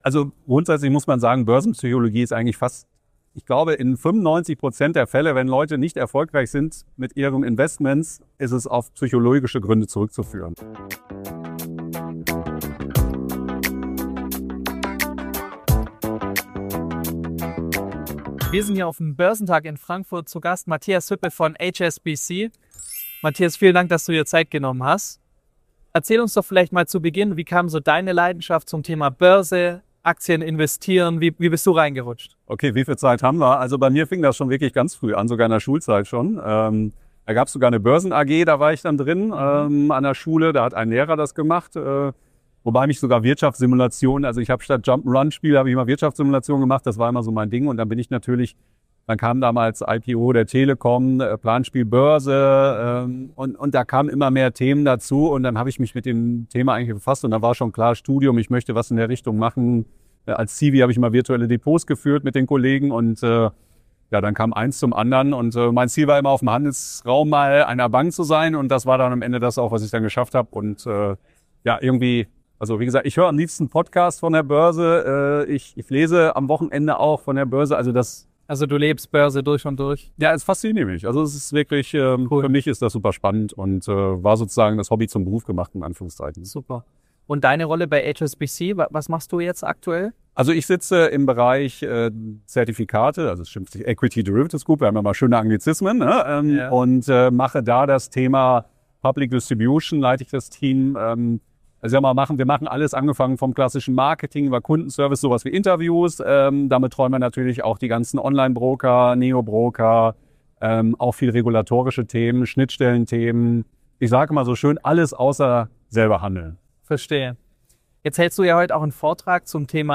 In diesem exklusiven Interview vom Börsentag Frankfurt erklärt